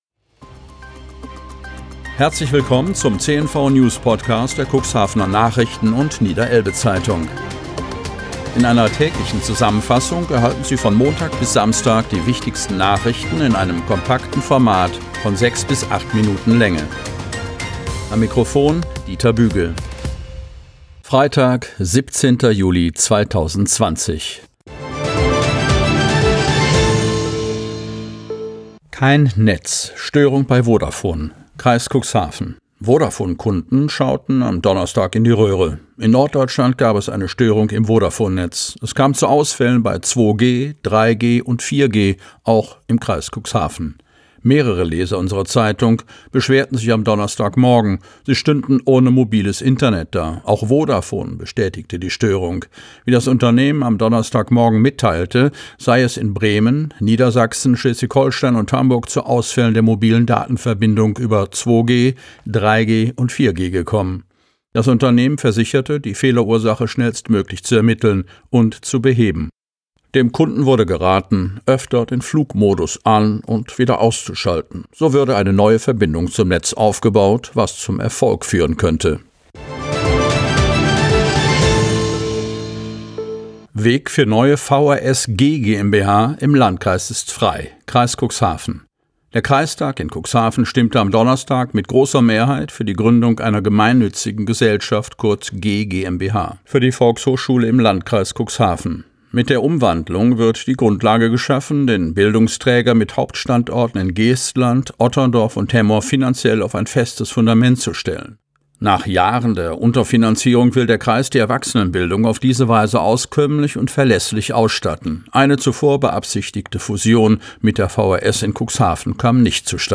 Die lokalen Nachrichten der Cuxhavener Nachrichten und der Niederelbe-Zeitung aus Cuxhaven und den gesamten Landkreis Cuxhaven.